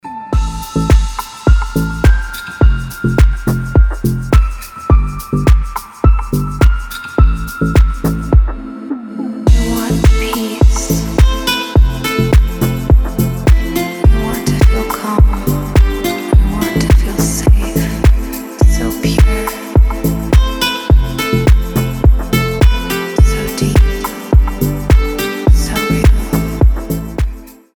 deep house
мелодичные
спокойные
Chill
Приятная успокаивающая музыка